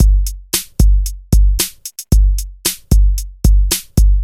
• 113 Bpm Drum Loop G Key.wav
Free drum loop - kick tuned to the G note.
113-bpm-drum-loop-g-key-fu6.wav